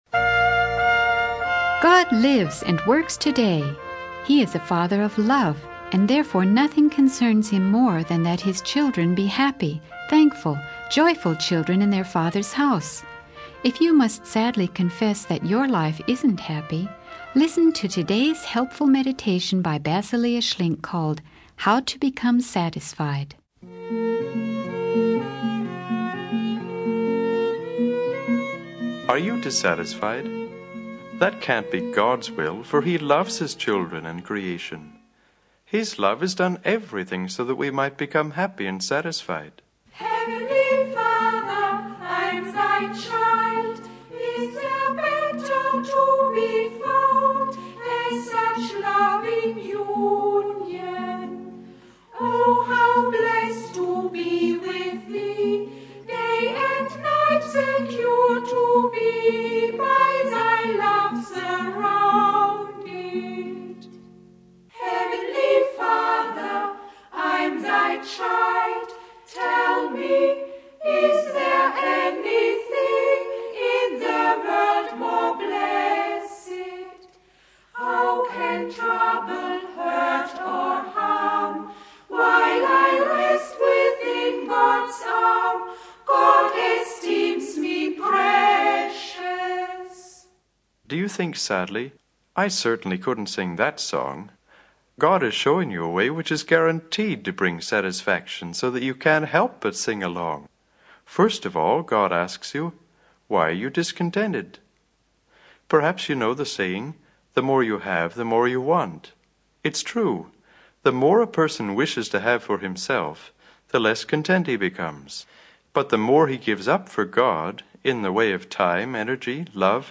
The video is a sermon on the topic of finding satisfaction and happiness in life.